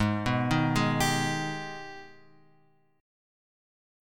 G# Minor Major 7th